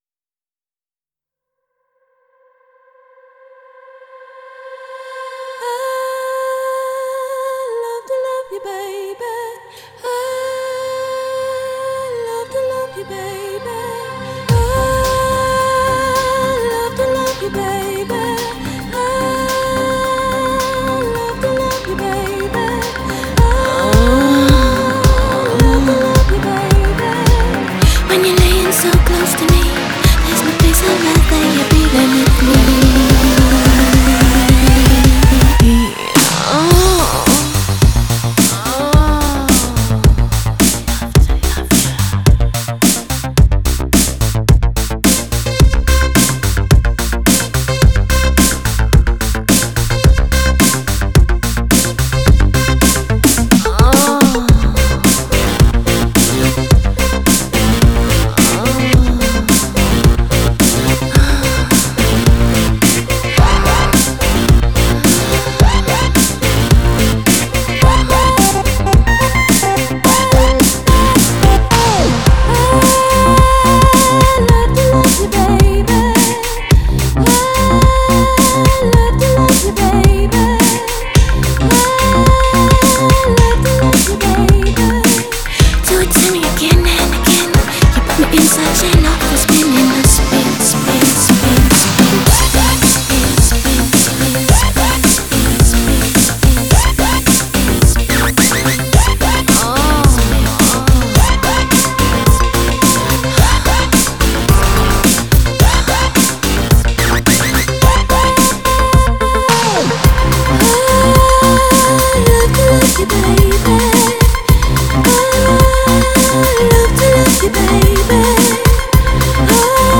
Genre: Dance, Disco, Nu-Disco, Funk